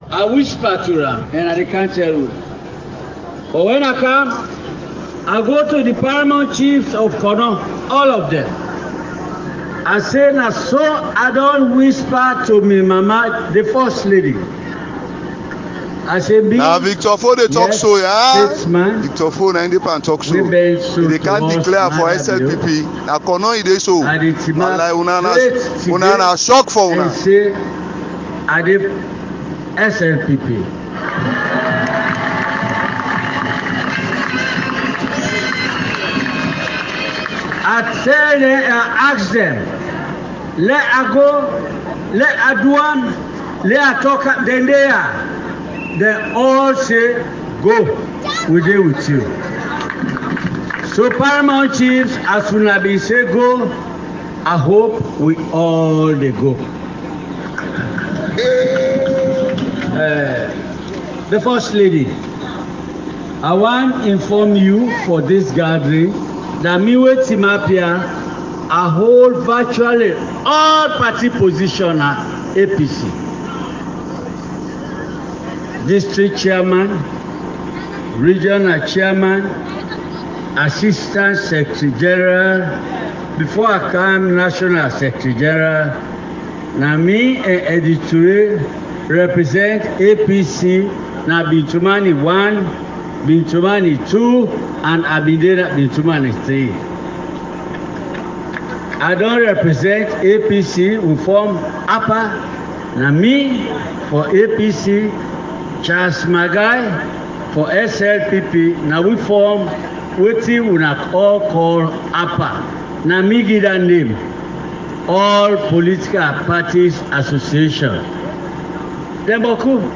The people of Koidu City, Kono District last Sunday, 30 April 2023 saw the opposition APC’s former Secretary General – Victor Bockarie Foh, declared his loyalty and support for President Bio and his SLPP party.